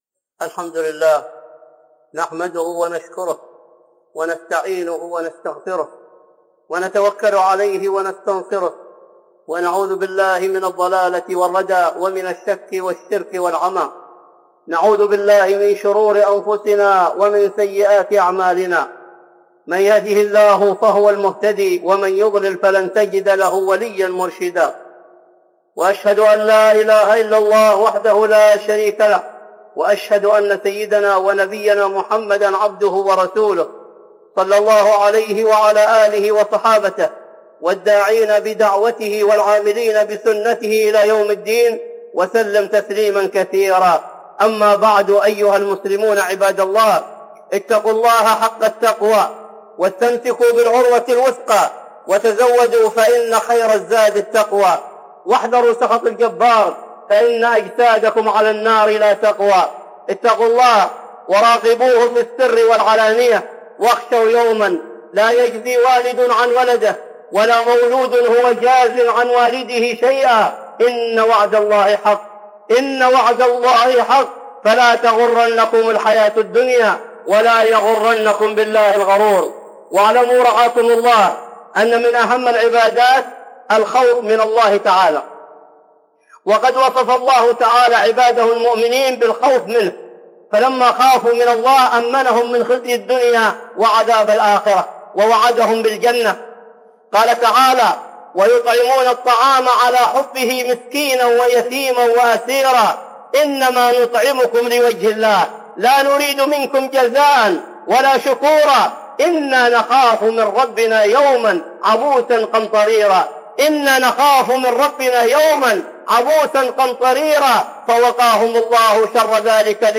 (خطبة جمعة) الخوف من الله